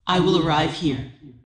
Subject description: Perhaps an extremely rare basic main battle tank with a female voice   Reply with quote  Mark this post and the followings unread
I am not a woman, this is using AI technology to replace my vocals with AI tones.